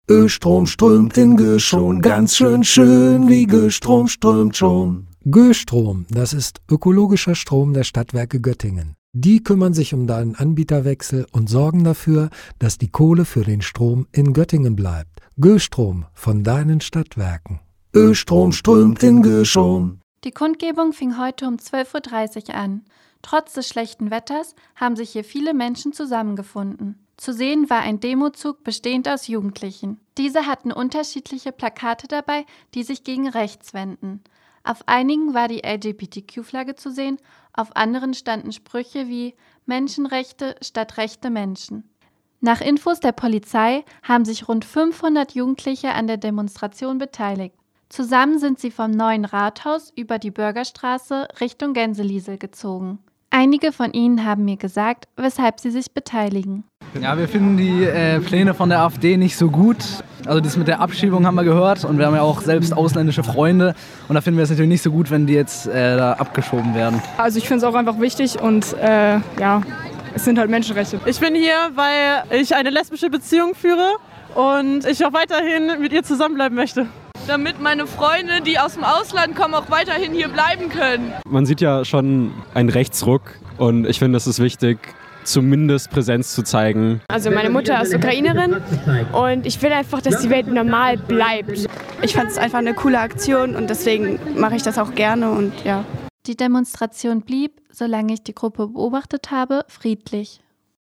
Letzten Sonntag haben sich bereits 15.000 Menschen in Göttingen getroffen, um gemeinsam gegen Rechts zu protestieren. Heute demonstrierten speziell Jugendliche.